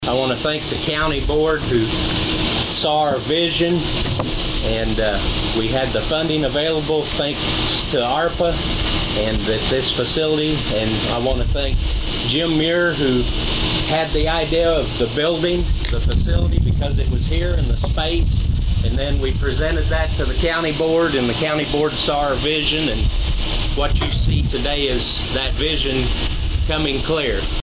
BENTON – State and county leaders, first responders, and community members showed up for Wednesday’s ribbon cutting ceremony and open house at the new Franklin County Coroner’s Office and Morgue in Benton.
Franklin County Coroner Marty Leffler.